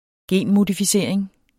Udtale [ ˈgeˀn- ]